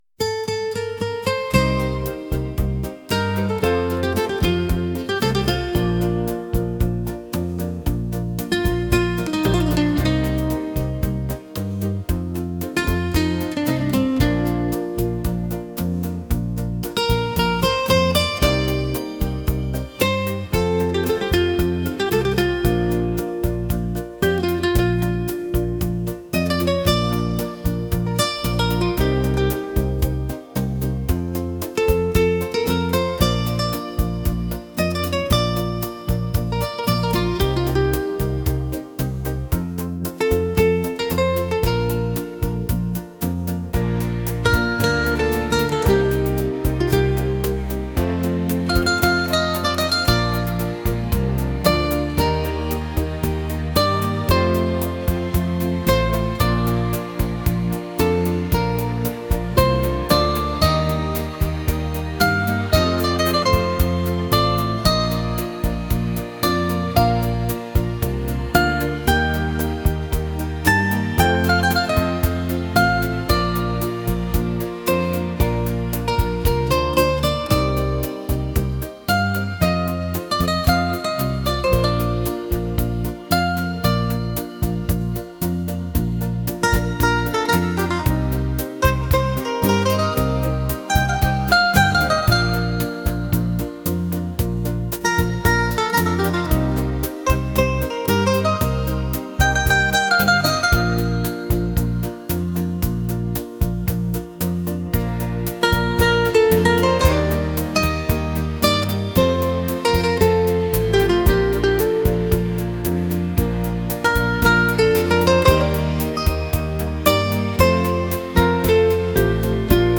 pop | romantic